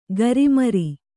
♪ garimari